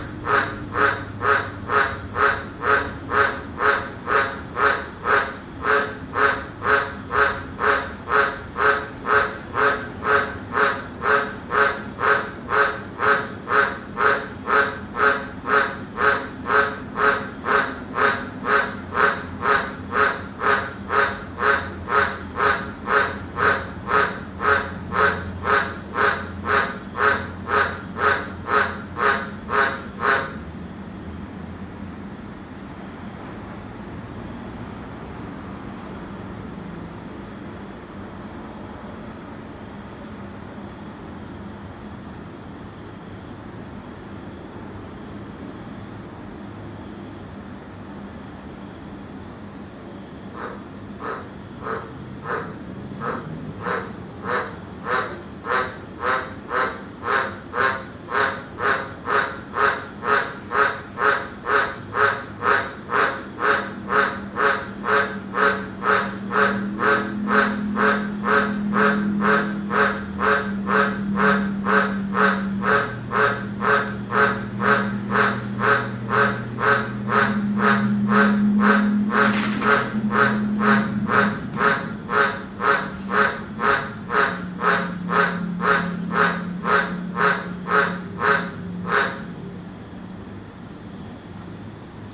自然界 " 蟋蟀
描述：蟋蟀唧啾叫在傍晚在一个温暖的潮湿的夏天晚上在布里斯班昆士兰澳大利亚